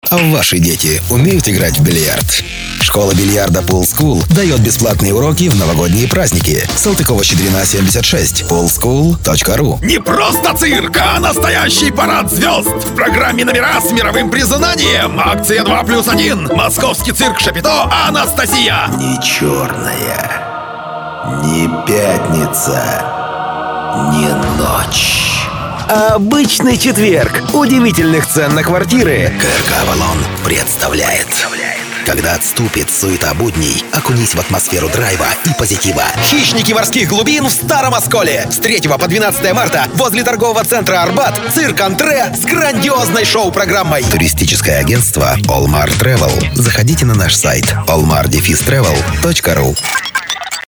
Баритон, озвучиваю рекламу более 16 лет! Универсальная подача (Всё зависит от заказчика).
Тракт: Профессиональная студия: Микрофон Rode NT 2000, пульт Yamaha MG 124cx, обработка DSP 1424P, карта RME